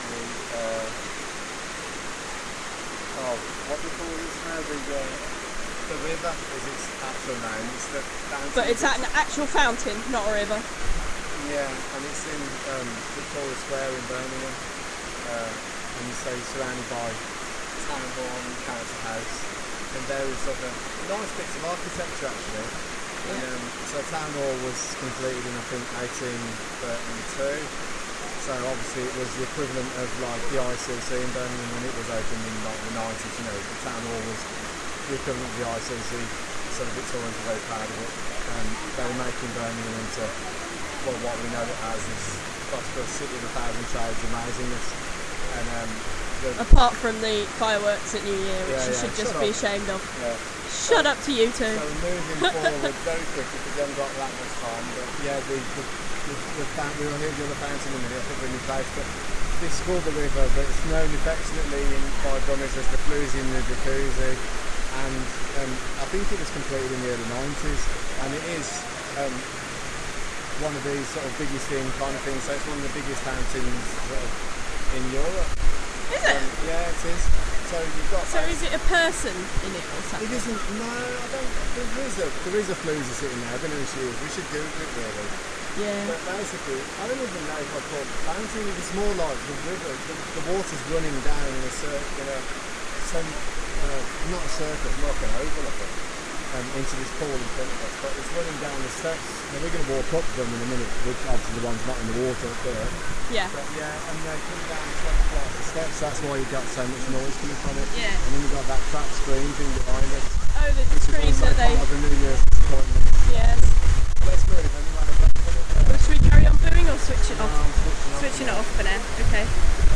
birmingham Waterfall